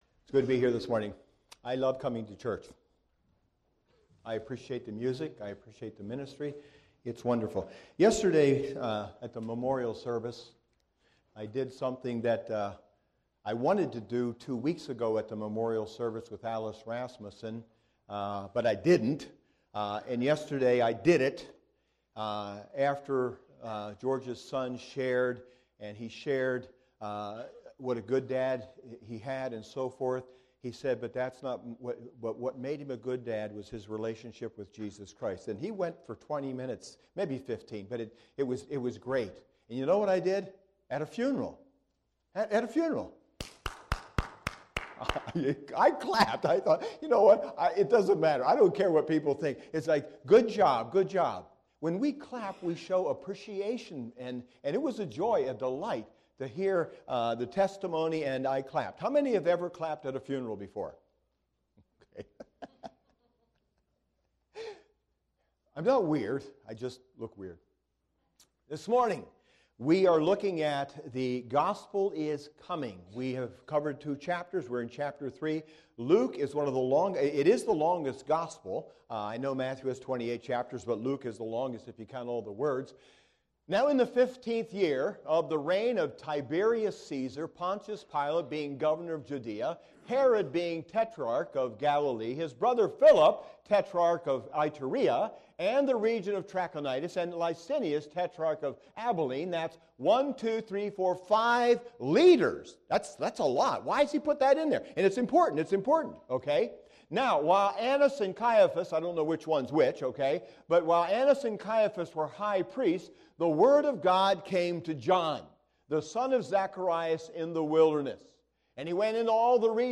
SERMONS - Grace Fellowship Church